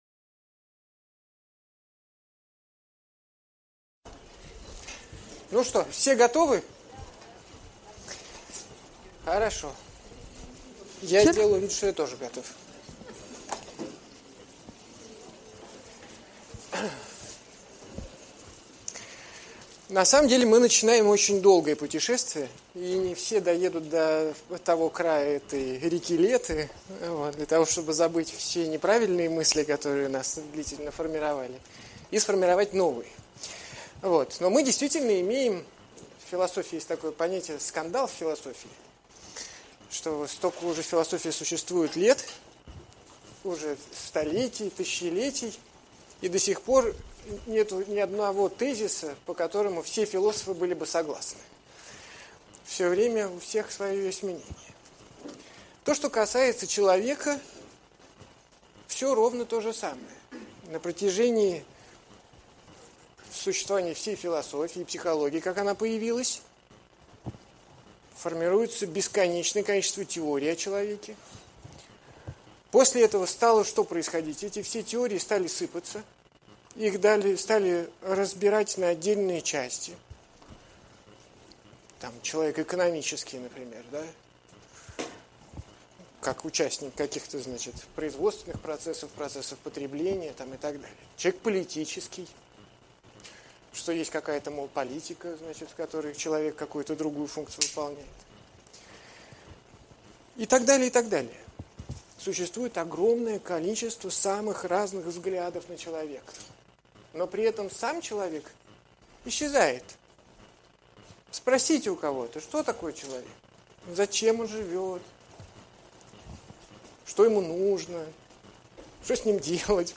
Аудиокнига Лекция №1 «Кто "я"?»
Автор Андрей Курпатов Читает аудиокнигу Андрей Курпатов.